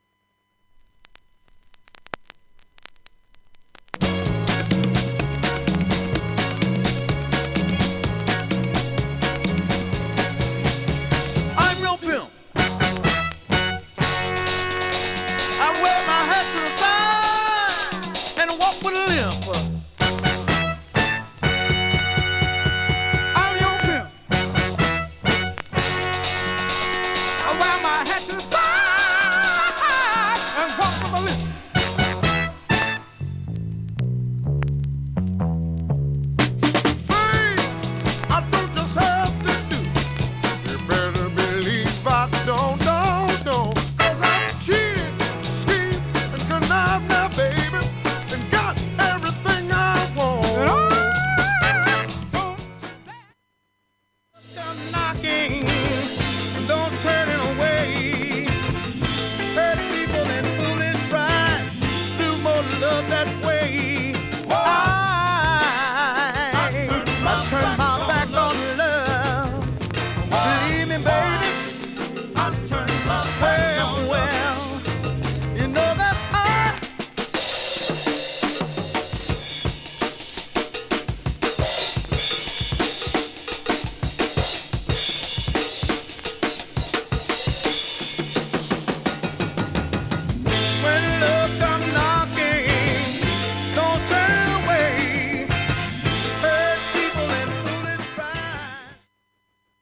B-1始めに3ミリキズ1本でプツ出る箇所あります。
B-2途中ブレイクはいい音質です。